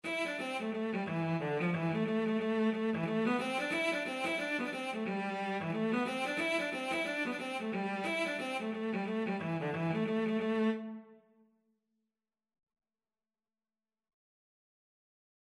Cello version
A minor (Sounding Pitch) (View more A minor Music for Cello )
2/4 (View more 2/4 Music)
Cello  (View more Easy Cello Music)
Traditional (View more Traditional Cello Music)